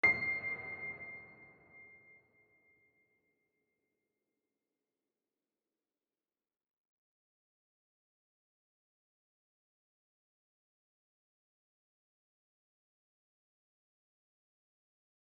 piano-sounds-dev
c6.mp3